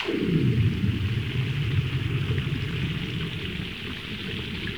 Index of /90_sSampleCDs/E-MU Producer Series Vol. 3 – Hollywood Sound Effects/Water/UnderwaterDiving
UNDERWATE02L.wav